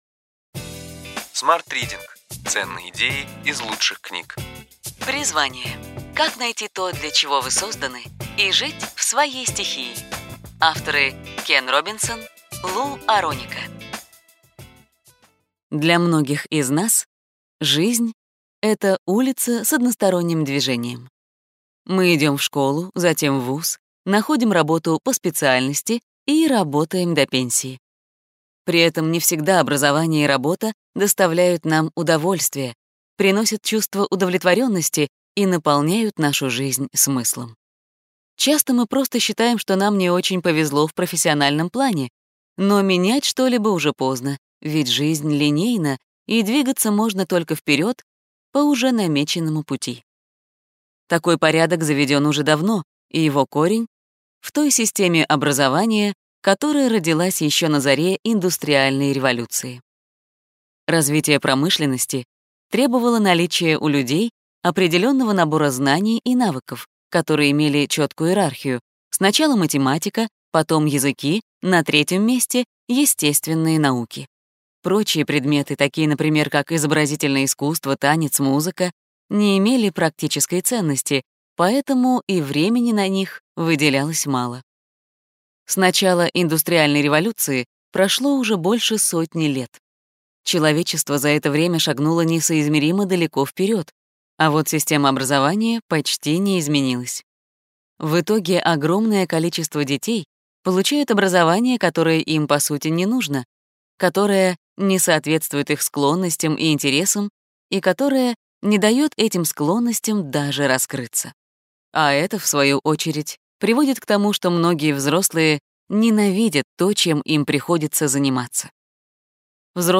Аудиокнига Ключевые идеи книги: Призвание. Как найти то, для чего вы созданы, и жить в своей стихии.